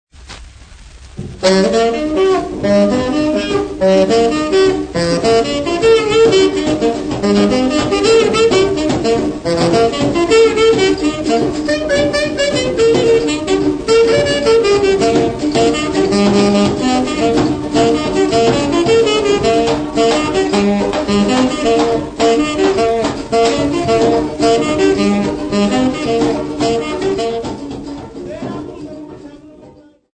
Umtali Chipisa Band
Popular music--Africa
Dance music
Field recordings
Africa Zimbabwe Mutare f-sa
Euro-African dance band